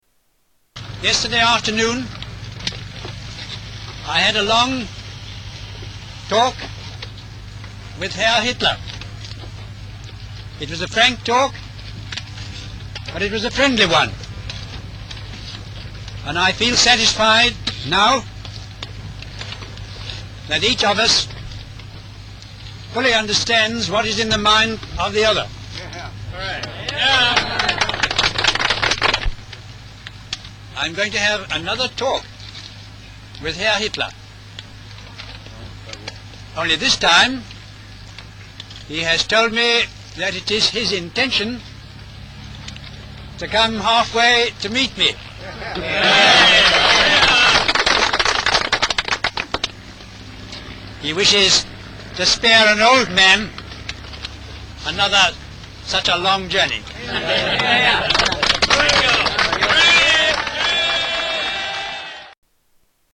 Tags: Historical Neville Chamberlain Audio Neville Chamberlain Speeches Arthur Neville Chamberlain Neville Chamberlain Sounds